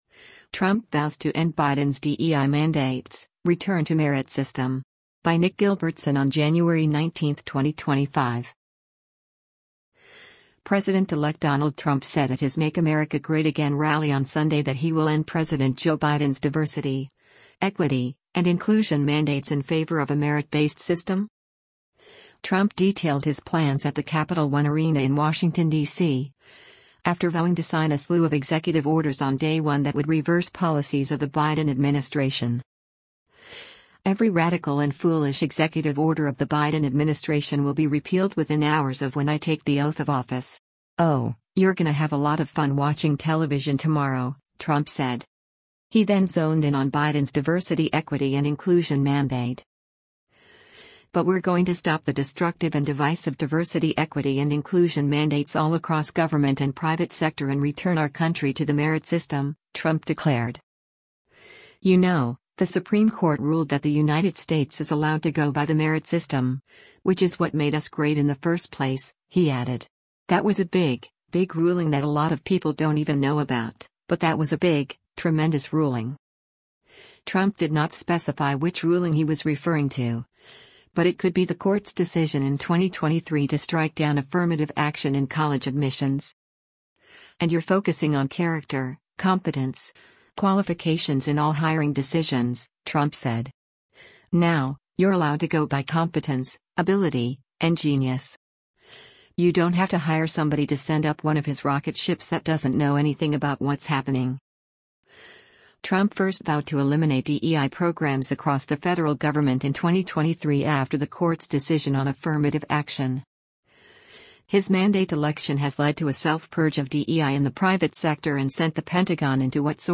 President-elect Donald Trump said at his Make America Great Again rally on Sunday that he will end President Joe Biden’s “diversity, equity, and inclusion mandates” in favor of a merit-based system.
Trump detailed his plans at the Capitol One Arena in Washington, DC, after vowing to sign a slew of executive orders on day one that would reverse policies of the Biden administration.